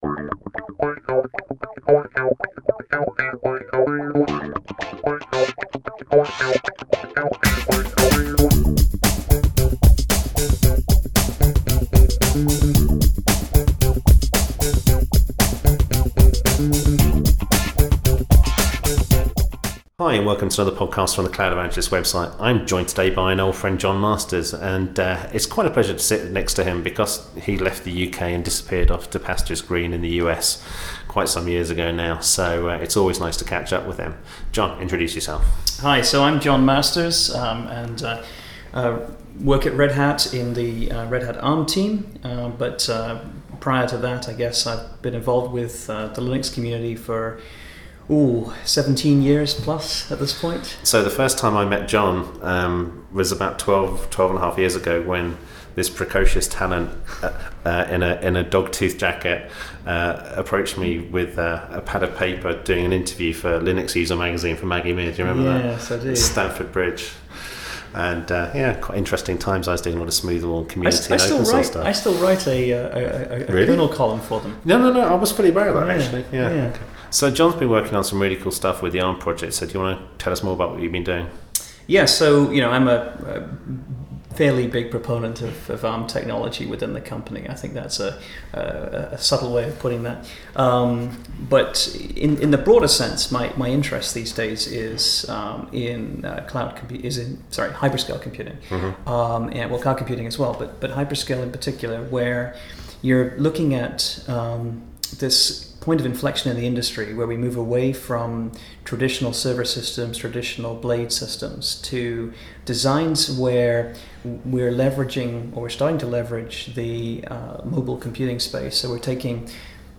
We're going to talk also about Raspberry PI, educational use of Open Source, our own programming backgrounds as fledgling talents back in the day. It's two geeks having a chat and talking technology as well as the goodness of emerging tech in the Cloud space.